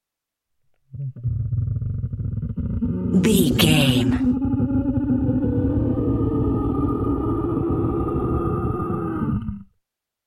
Dinosaur snarl close pain
Sound Effects
scary
ominous
angry